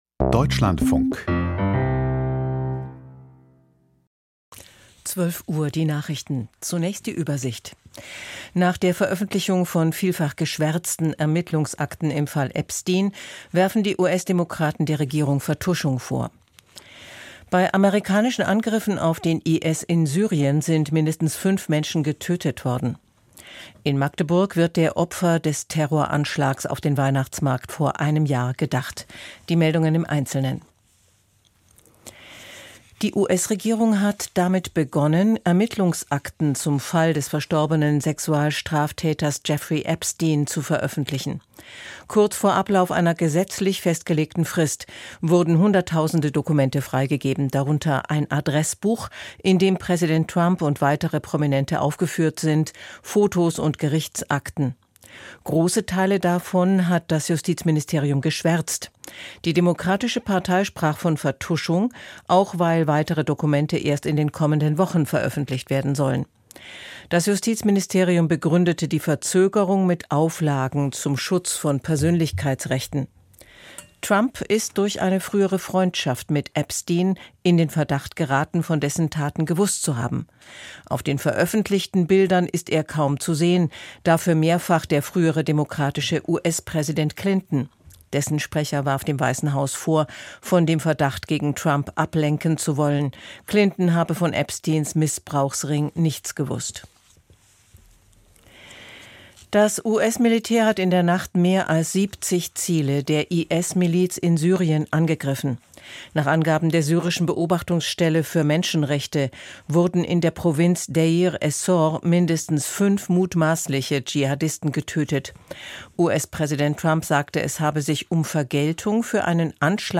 Die Nachrichten vom 20.12.2025, 12:00 Uhr